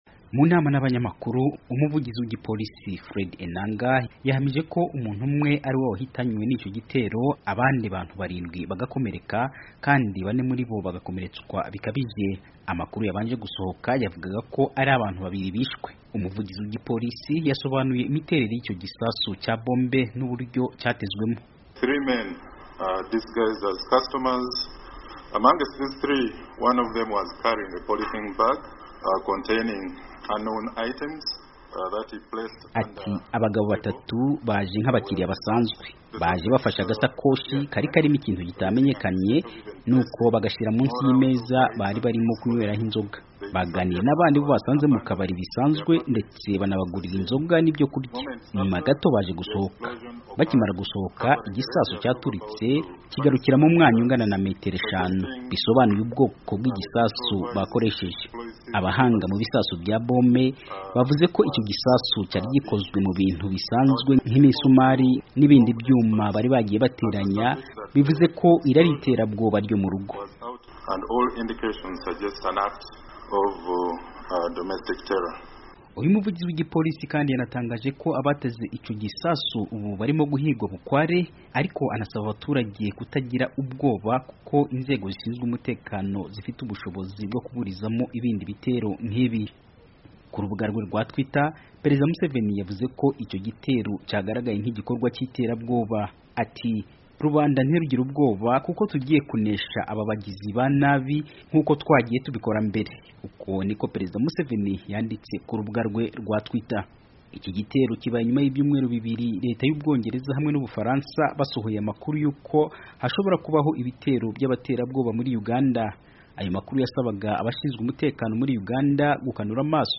akorera i Kampala muri Uganda aratubwira ibindi kuri iyi nkuru mu majwi.